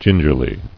[gin·ger·ly]